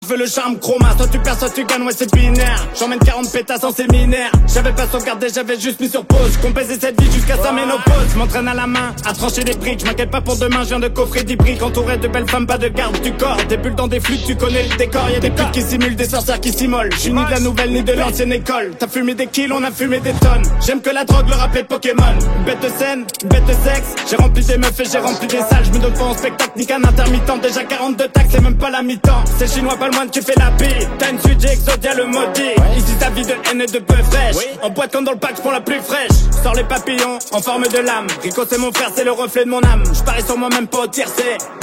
Rap / Hip Hop